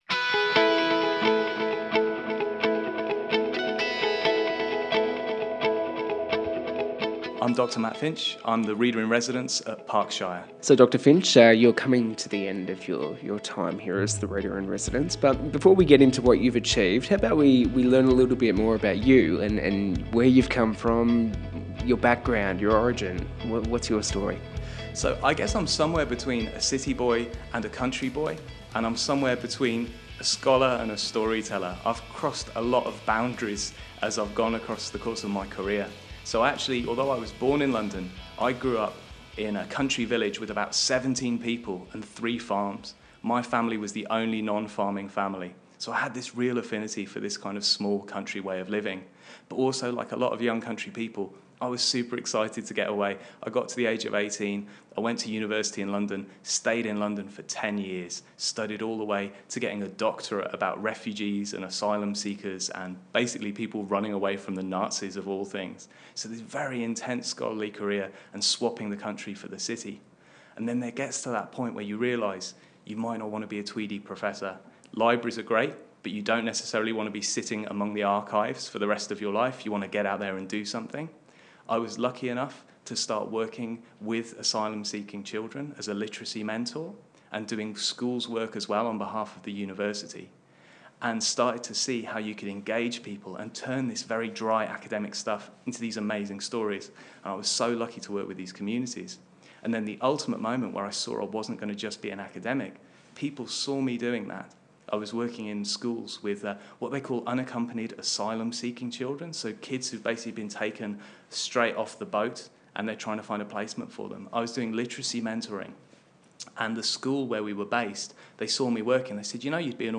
I’ve just surfaced this week to announce that Parkes Library’s monsters-versus-robots Big Box Battle roleplay received coverage in The Huffington Post. Also, you can now listen to my recent interview with the Australian Broadcasting Corporation right here!